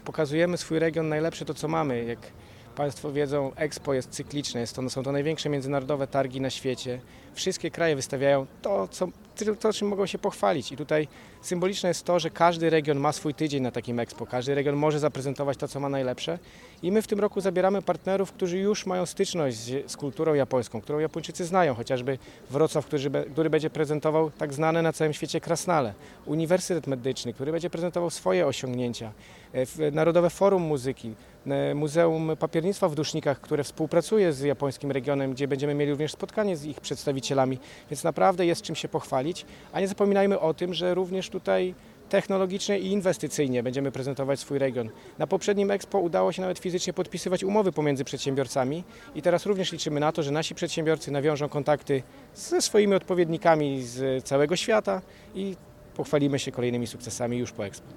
Japończycy to fani zabytków, zamków i pałaców, a pamiętajmy, że to właśnie na Dolnym Śląsku mamy ich najwięcej w kraju, liczymy zatem na sukces – mówi Michał Rado, wicemarszałek województwa dolnośląskiego.